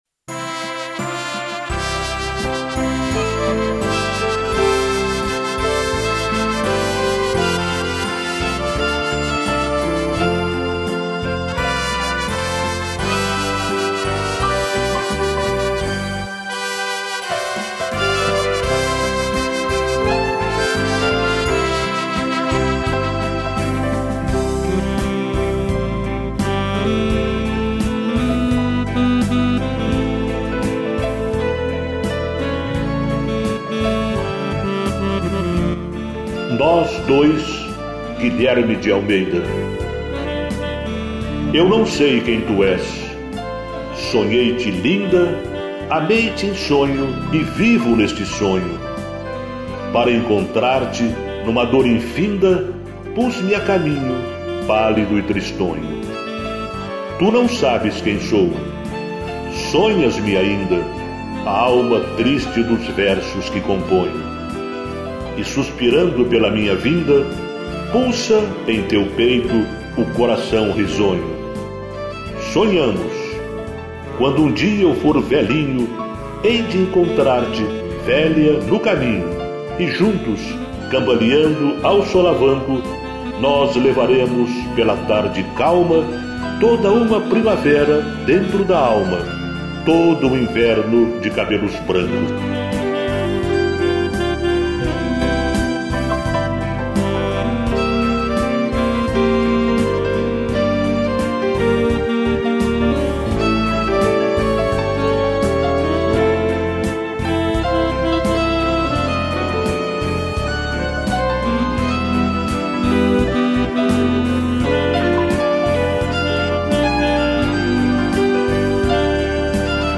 piano, cello e violino